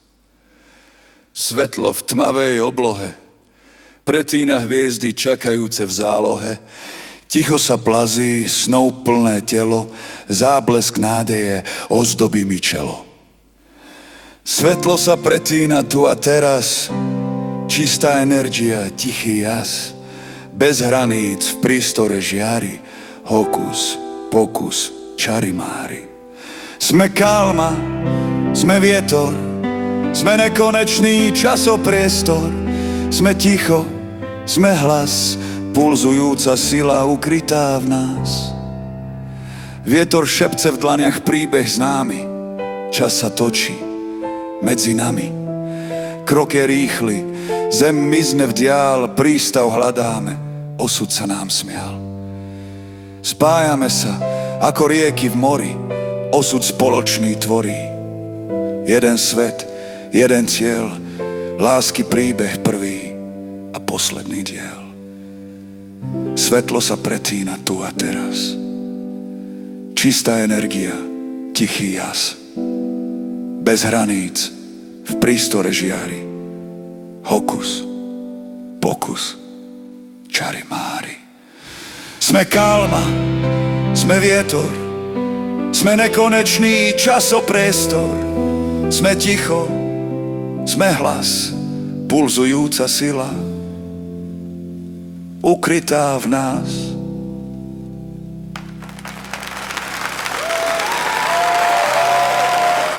Recitovanie AI